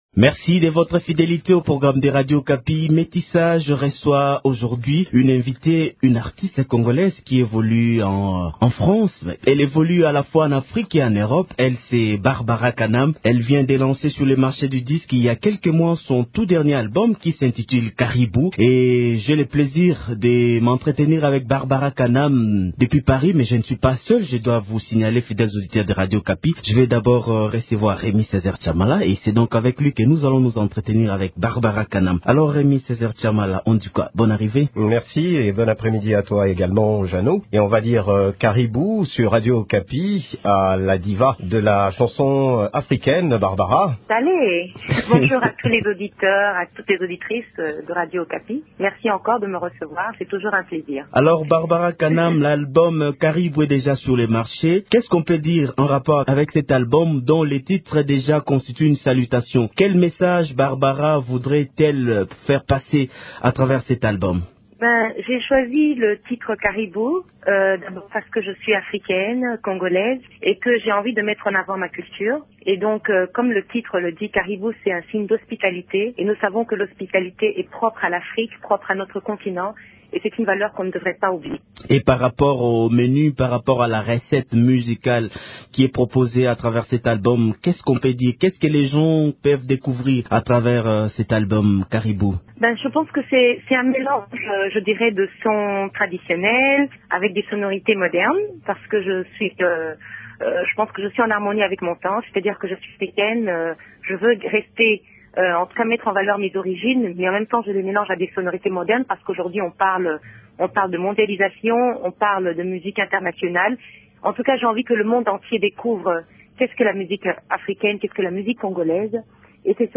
Joint au téléphone depuis Paris